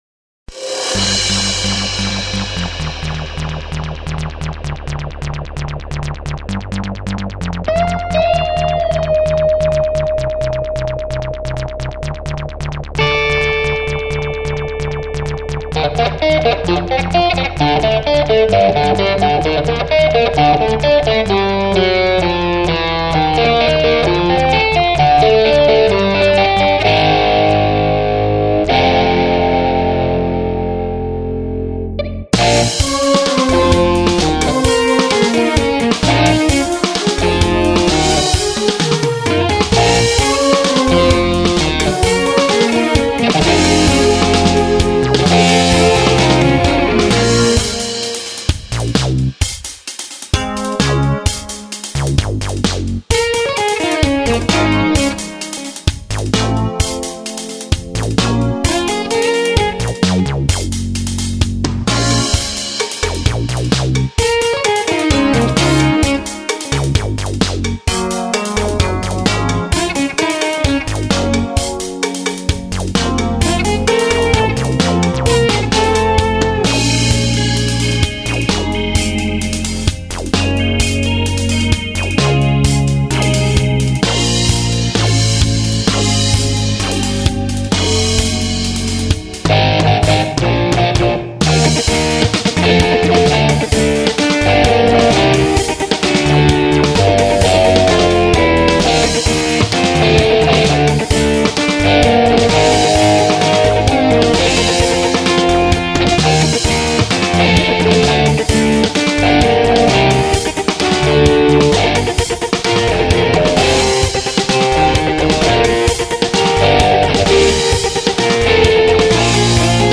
Drum/Bass/PadBrass/E.gtr/E.Piano